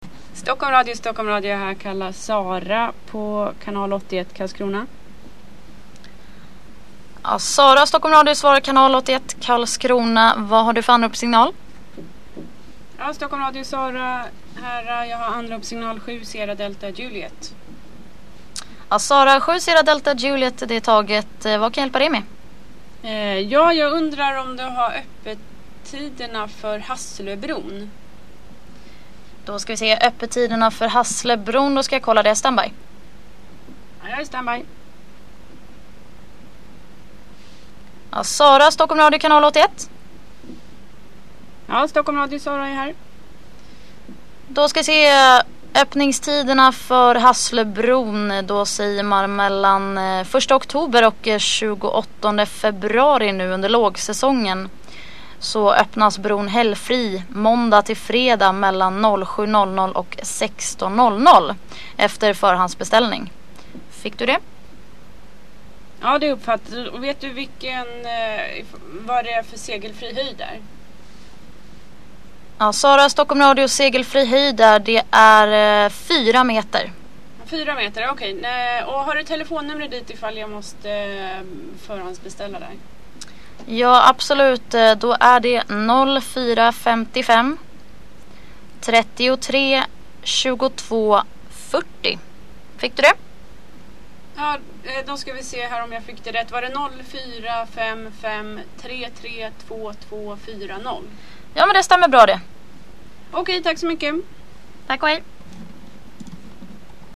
HÄR KAN DU LYSSNA PÅ HUR ETT VHF-ANROP TILL STOCKHOLMRADIO KAN LÅTA: